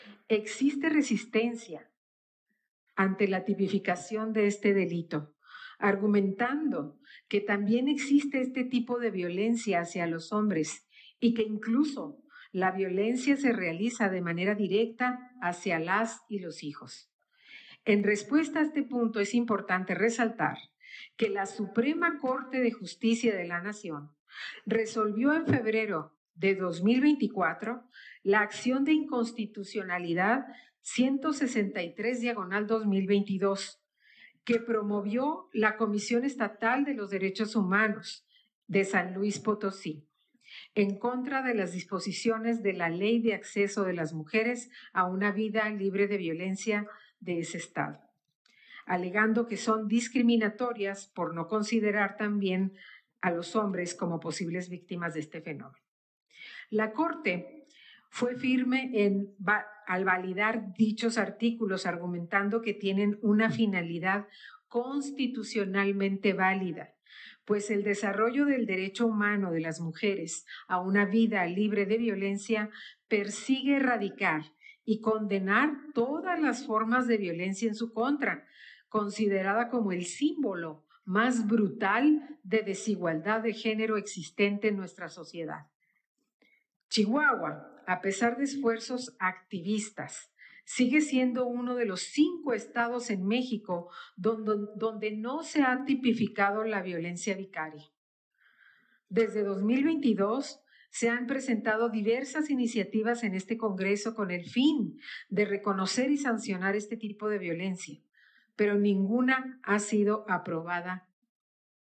En sesión del Congreso del Estado, la diputada Leticia Ortega Máynez presentó una iniciativa para reformar la Ley Estatal del Derecho de las Mujeres a una Vida Libre de Violencia , el Código Penal y el Código Civil del Estado de Chihuahua , con el objetivo de reconocer y sancionar la violencia vicaria como una forma extrema de violencia de género.